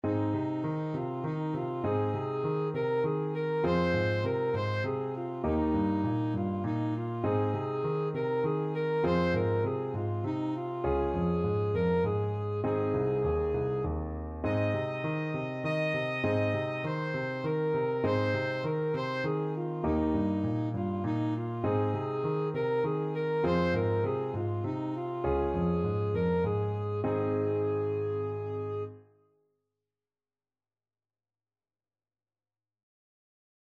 Alto Saxophone
"Arirang" is a Korean folk song, often considered as the unofficial national anthem of Korea.
Ab major (Sounding Pitch) F major (Alto Saxophone in Eb) (View more Ab major Music for Saxophone )
3/4 (View more 3/4 Music)
Eb5-Eb6
Traditional (View more Traditional Saxophone Music)